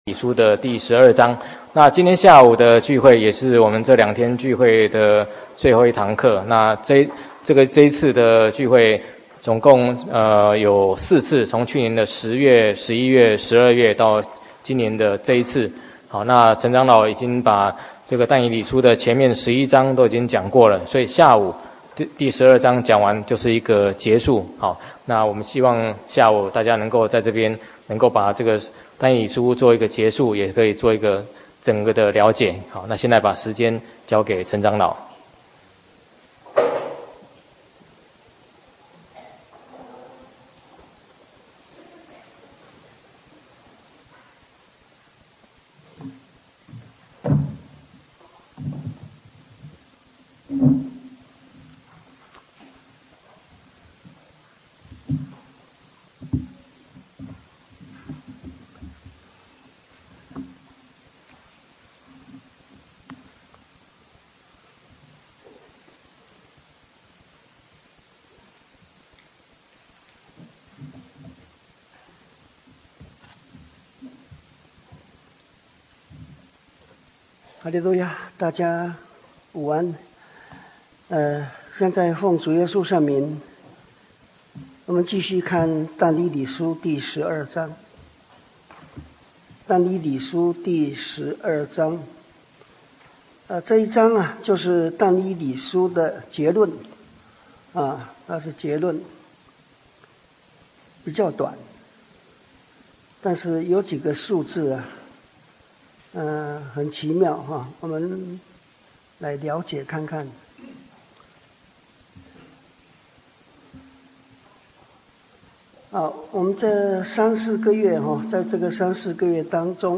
但以理書(十二)-講道錄音